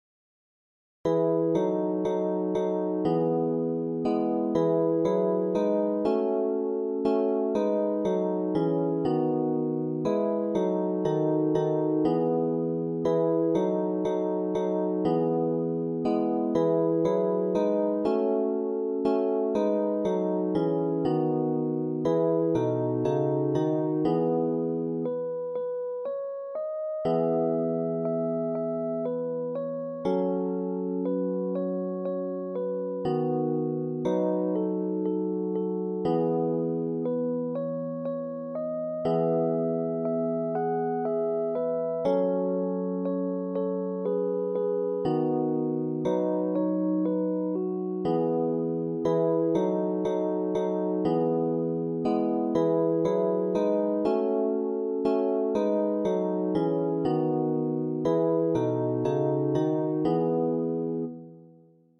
• Catégorie : Chants de Méditation